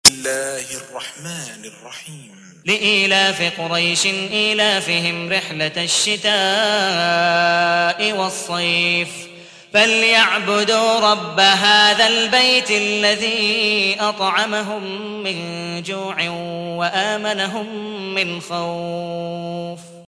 سورة قريش / القارئ